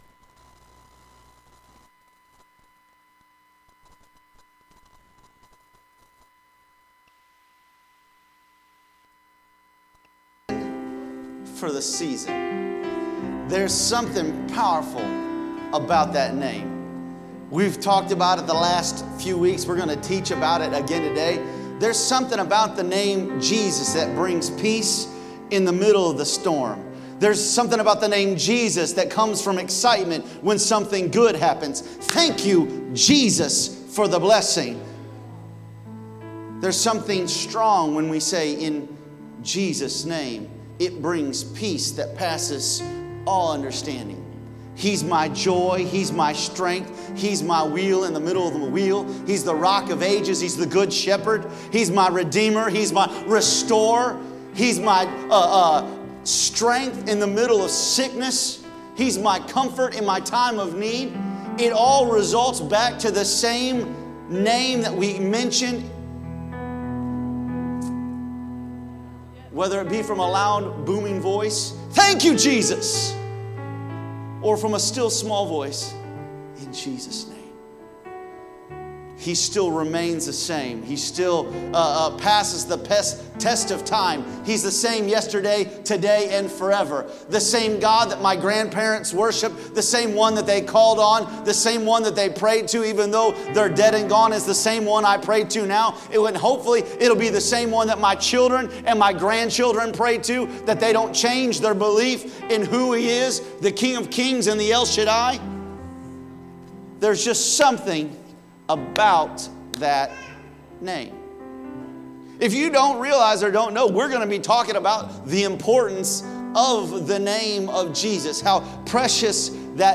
Sermons | Elkhart Life Church
Sunday Service - Part 23